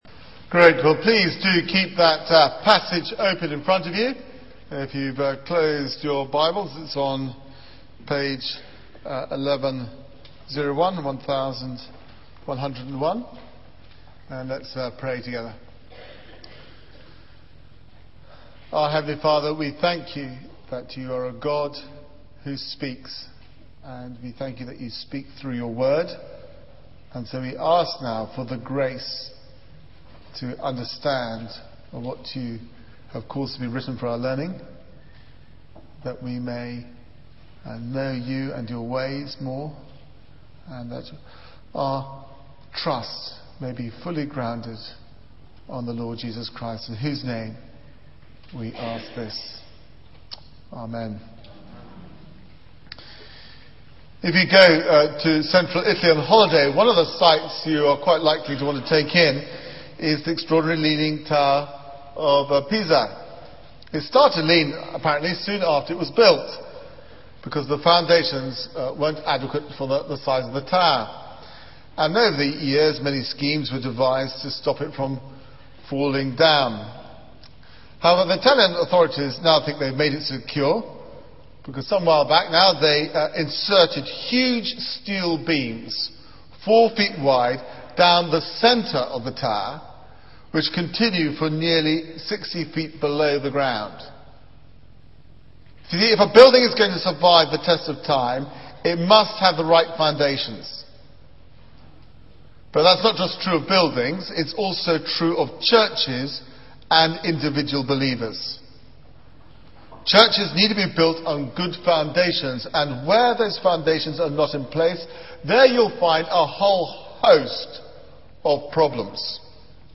Media for 9:15am Service on Sun 14th Jun 2009 09:15 Speaker: Passage: Acts 8: 5 - 25 Series: Foundations for World Mission Theme: False Conversion Sermon Search the media library There are recordings here going back several years.